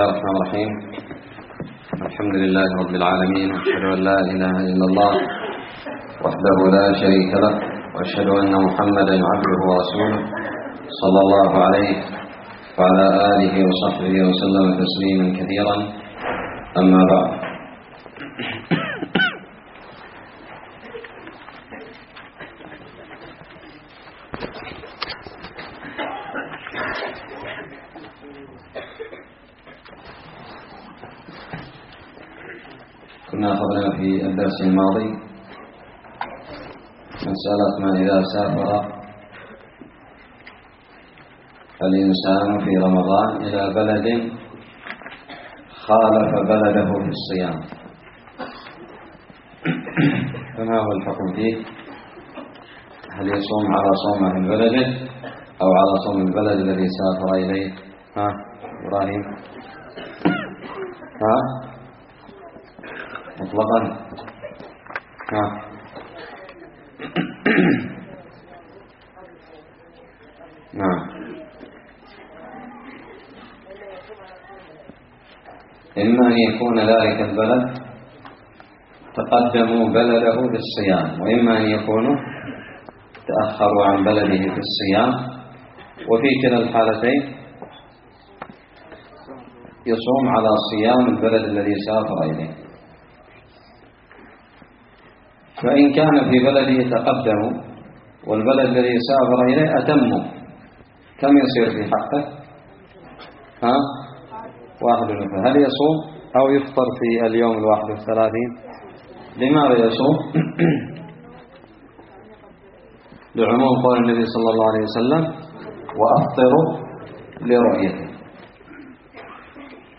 الدرس الثامن من كتاب الصيام من الدراري
ألقيت بدار الحديث السلفية للعلوم الشرعية بالضالع